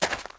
gravel4.ogg